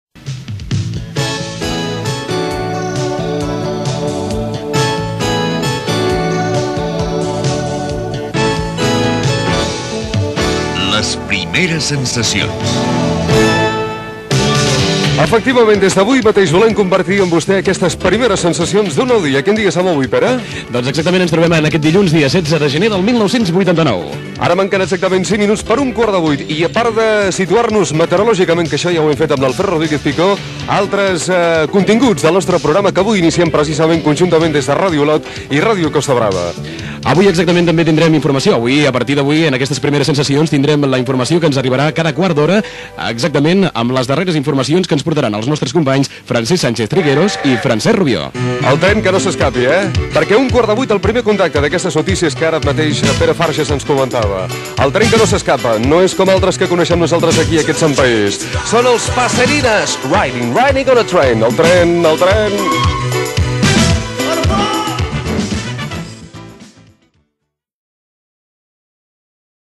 Data, hora, previsió de continguts i tema musical.
Info-entreteniment
Magazín de matí emès conjuntament per Ràdio Olot i Ràdio Costa Brava.